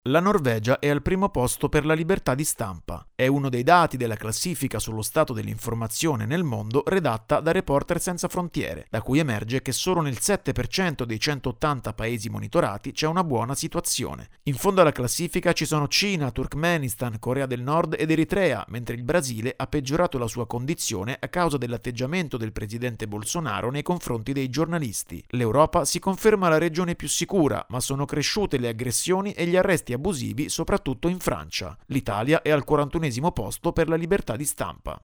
Libertà di stampa, il dossier annuale di Reporter Senza Frontiere - Giornale Radio Sociale